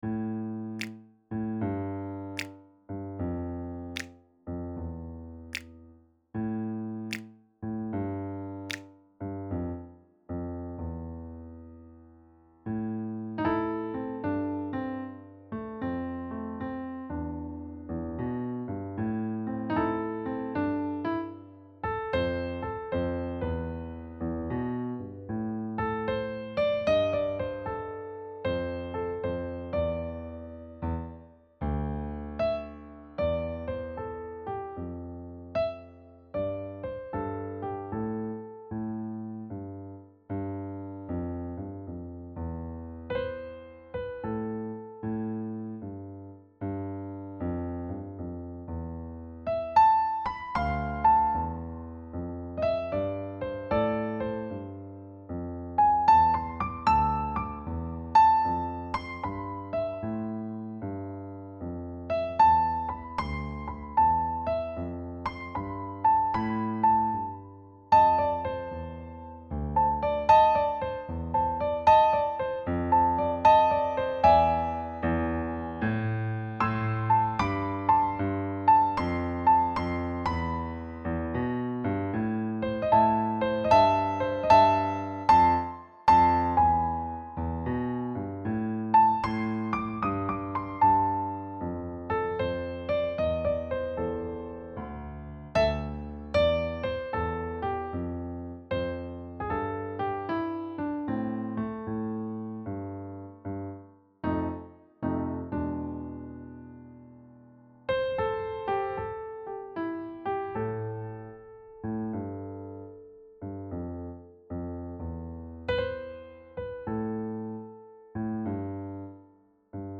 Key: A Minor Blues
Time Signature: 4/4 (BPM = 76)